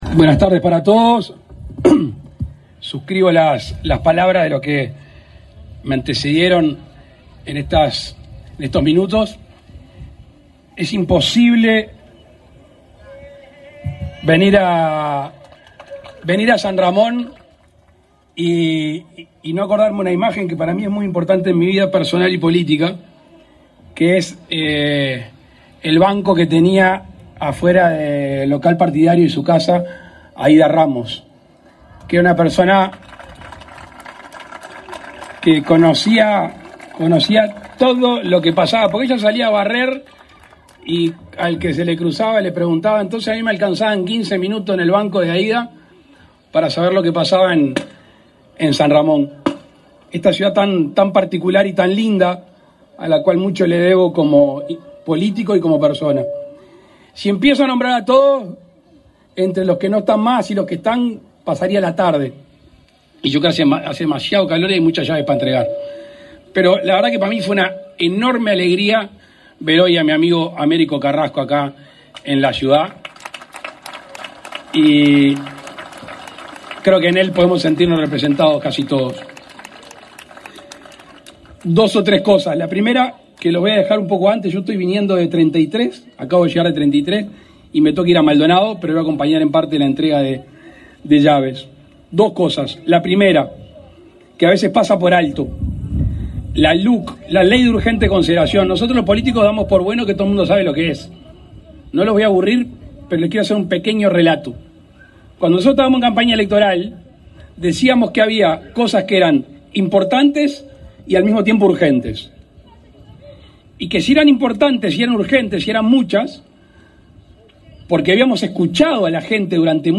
Palabras del presidente de la República, Luis Lacalle Pou
El presidente de la República, Luis Lacalle Pou, participó, este 18 de octubre, en el acto de inauguración del plan de viviendas de Mevir Dr. Oscar